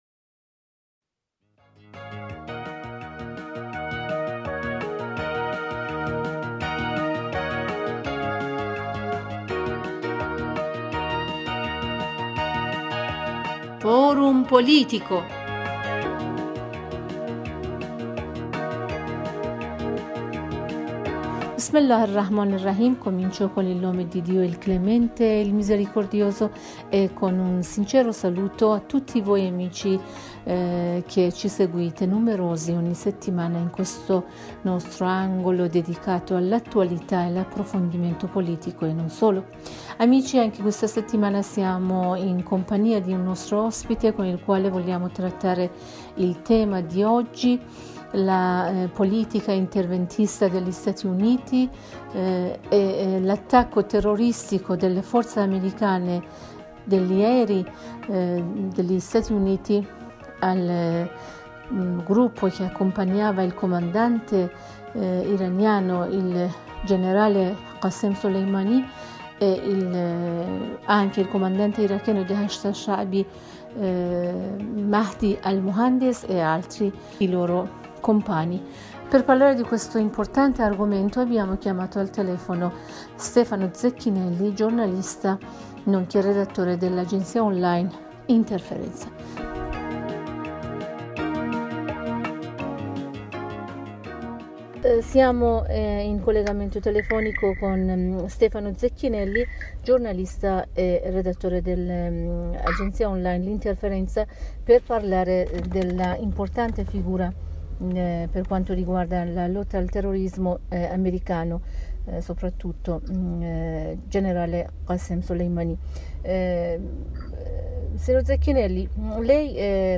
e' stato intervistato dalla nostra Redazione.